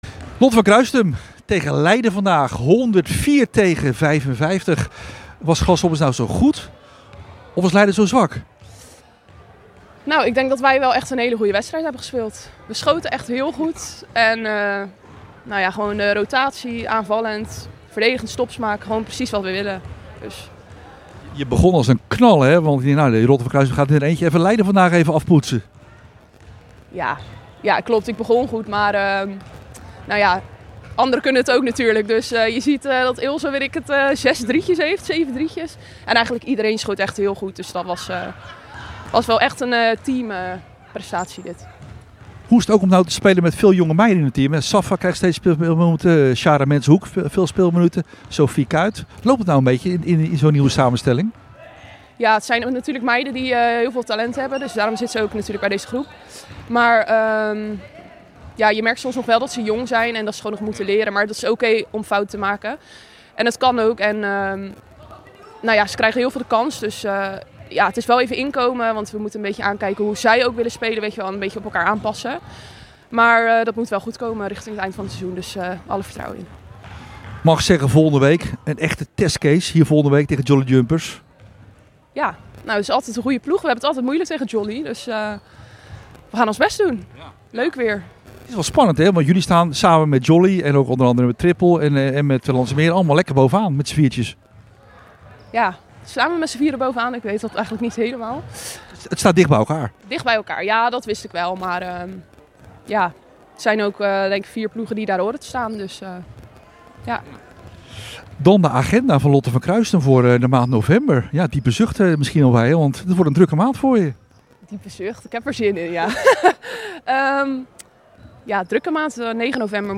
Na afloop van de wedstrijd